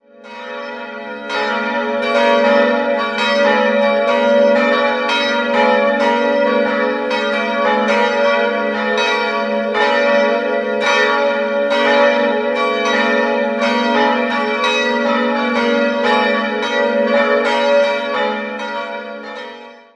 3-stimmiges Gloria-Geläut: as'-b'-des'' Die beiden kleineren Glocken wurden im Jahr 1962 von Friedrich Wilhelm Schilling gegossen, die große Eisenhartgussglocke stammt aus dem Jahr 1949 von Ulrich&Weule (Apolda).